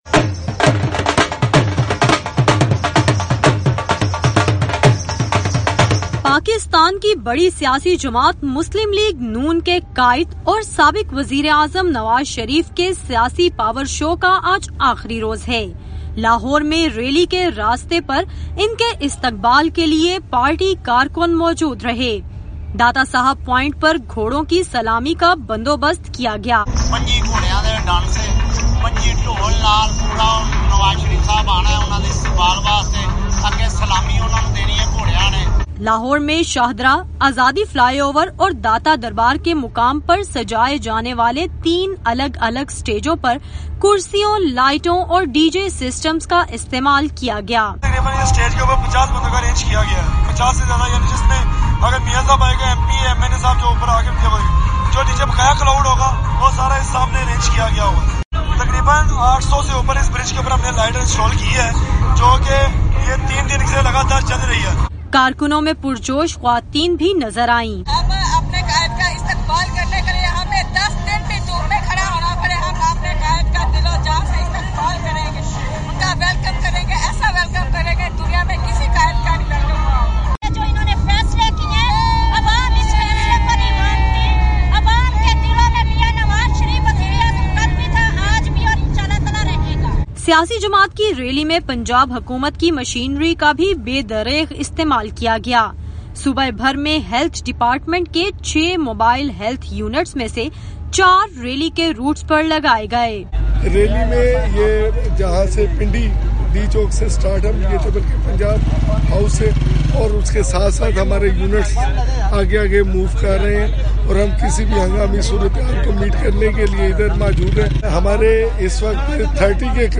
نواز شریف نے لاہور میں ریلی سے جارحانہ خطاب کیا اور بعد میں رائیونڈ پہنچے۔